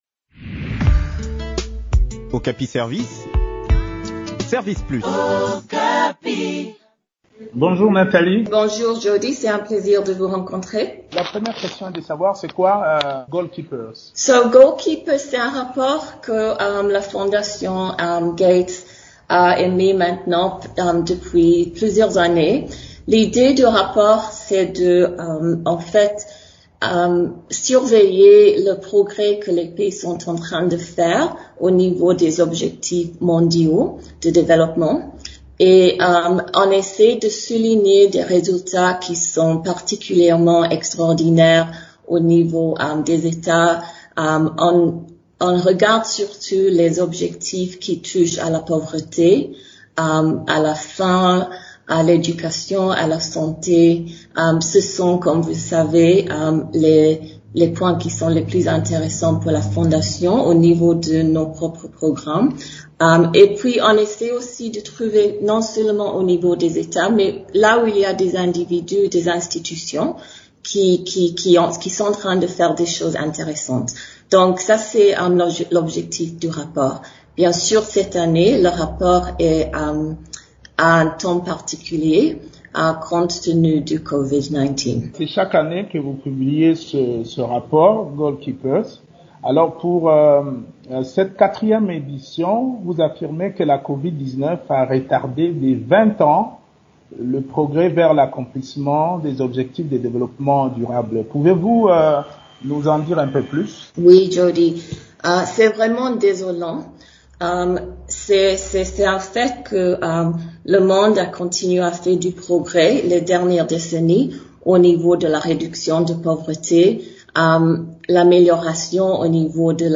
Les détails dans cet entretien